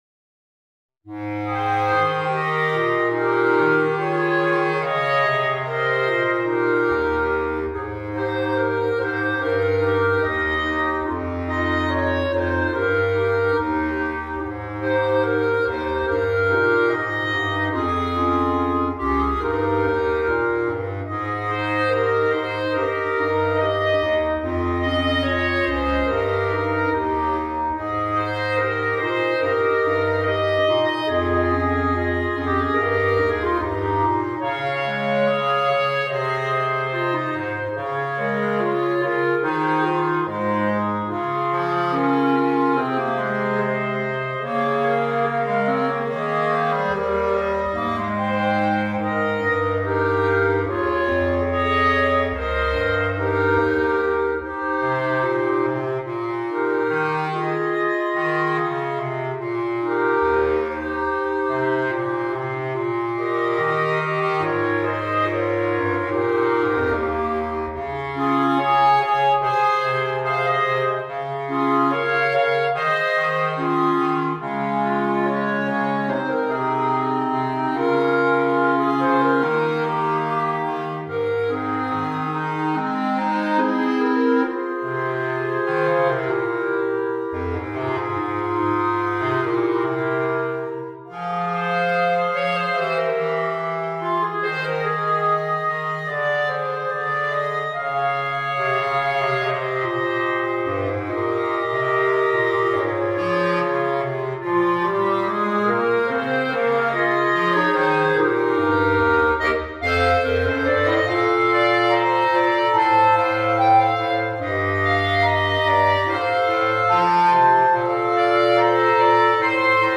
(for Clarinet Quartet)
easy arrangement